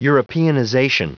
Prononciation du mot europeanization en anglais (fichier audio)
Prononciation du mot : europeanization